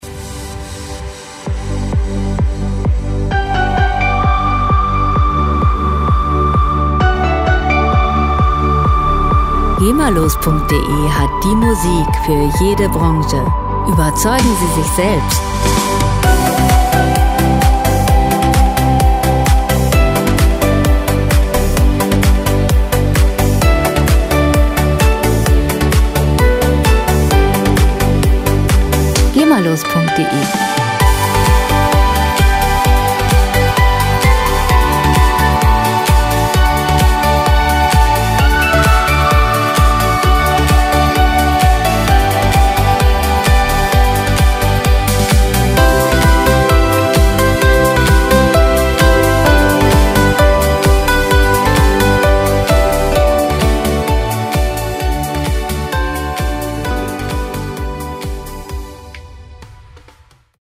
Musikstil: Schlager Pop
Tempo: 130 bpm
Tonart: G-Dur
Charakter: harmonisch, leicht
Instrumentierung: Synthesizer, E-Gitarre, E-Bass, Drums